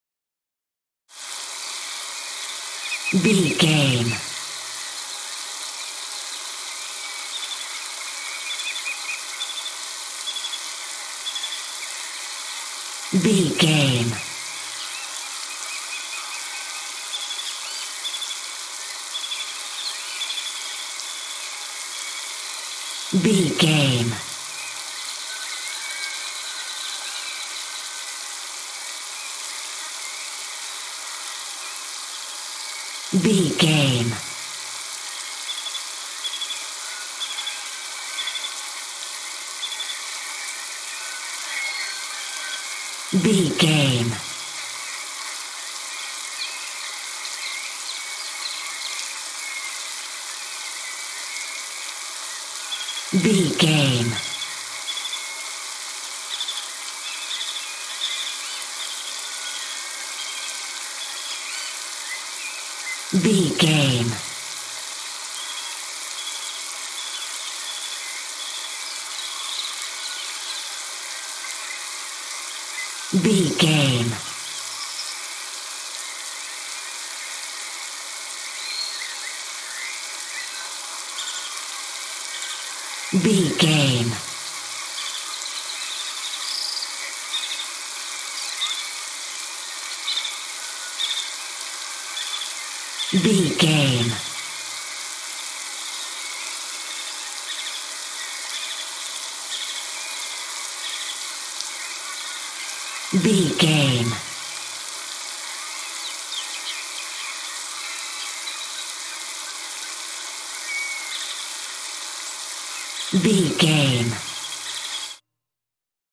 Country ambience lake rain
Country ambience lake rain 15
Sound Effects
calm
peaceful
ambience